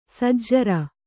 ♪ sajjara